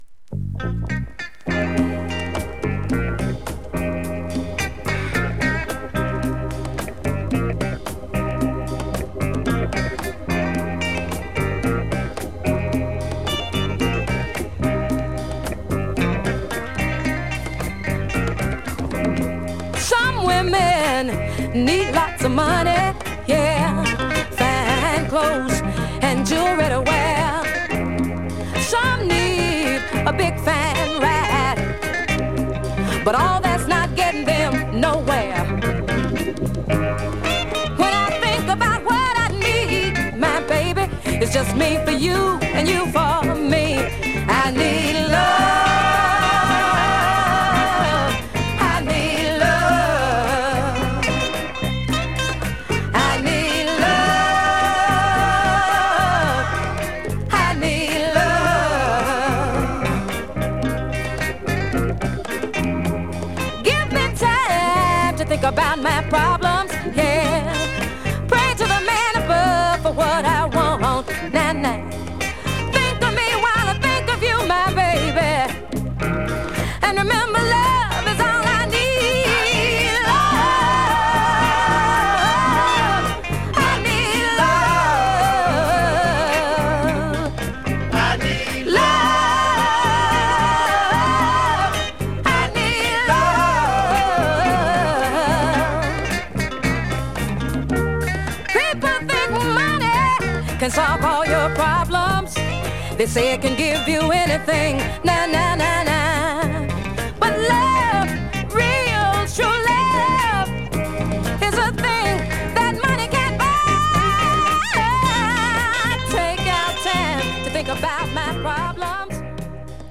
◇小キズが少々あり曲によってプツ音目立つ箇所あります